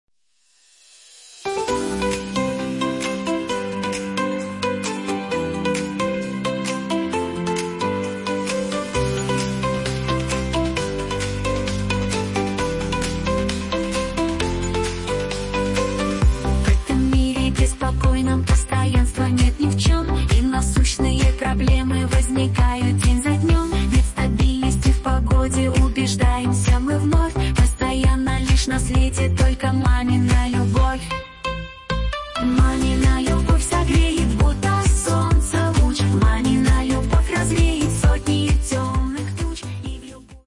Фрагмент исполнения 1-го варианта (динамичный)